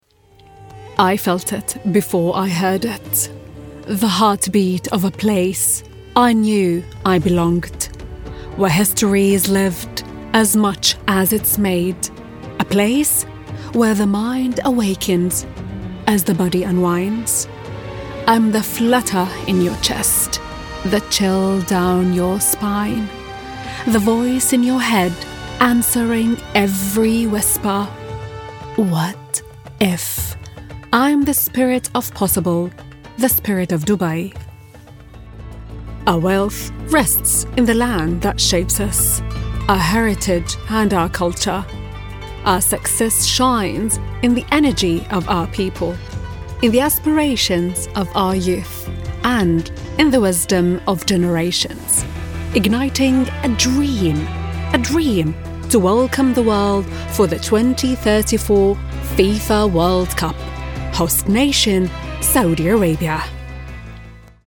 Arabic (Jordan), Middle Eastern, Female, 20s-40s